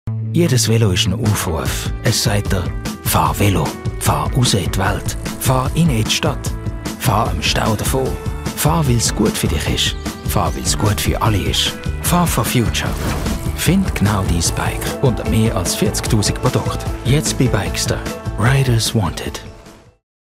Werbung Schweizerdeutsch (ZH)